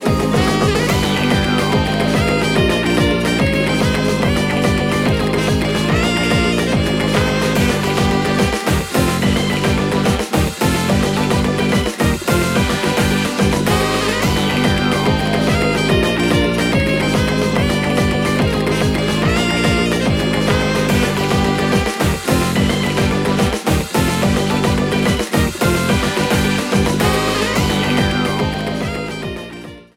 A boss battle theme
Ripped from game
clipped to 30 seconds and applied fade-out